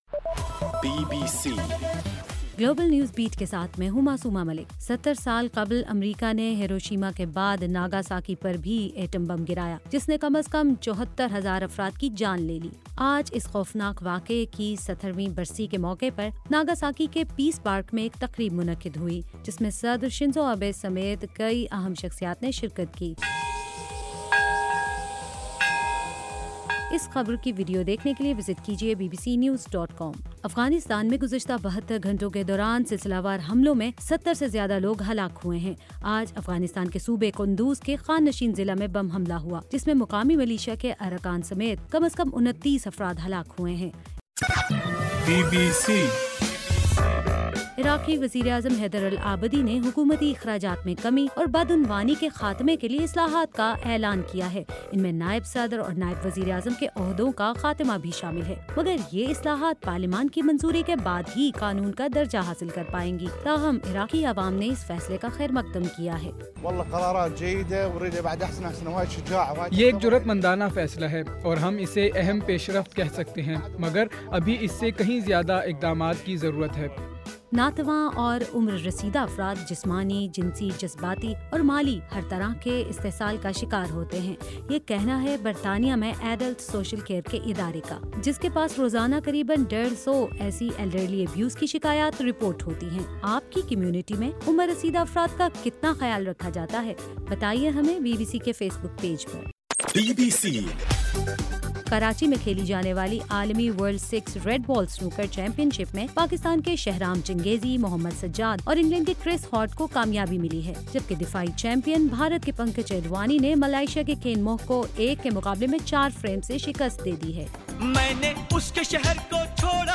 اگست 9: رات 10 بجے کا گلوبل نیوز بیٹ بُلیٹن